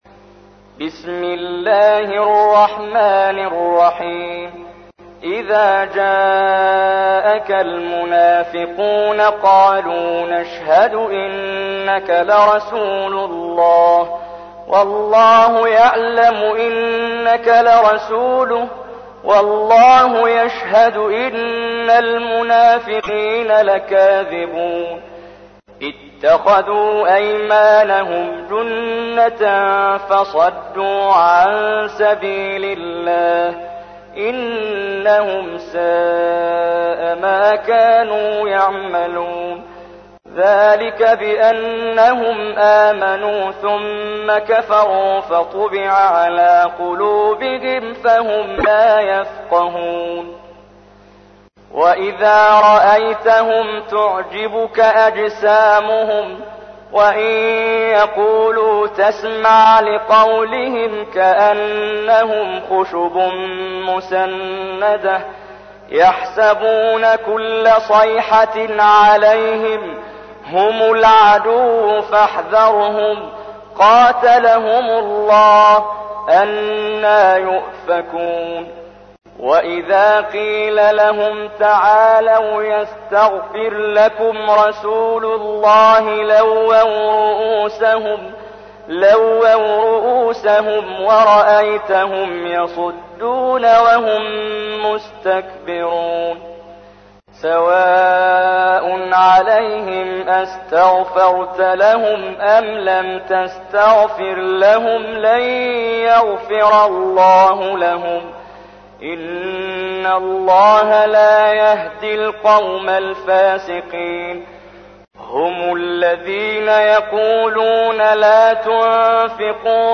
تحميل : 63. سورة المنافقون / القارئ محمد جبريل / القرآن الكريم / موقع يا حسين